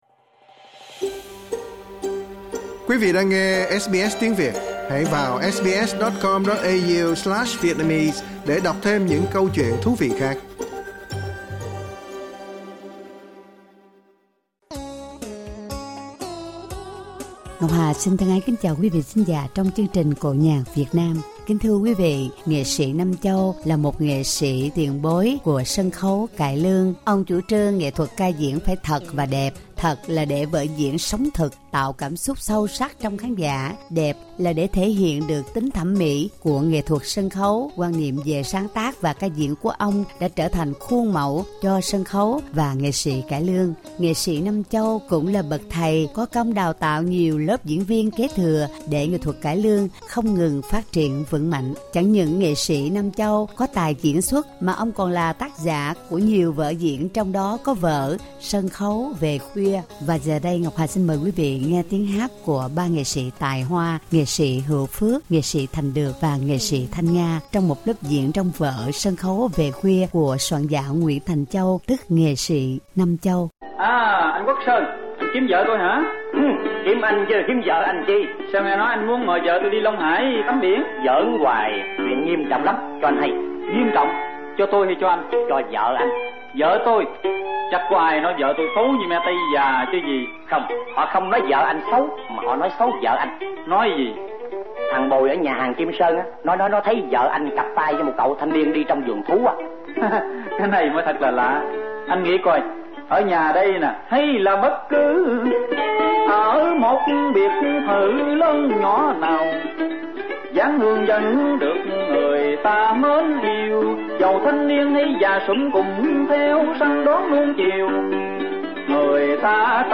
cải lương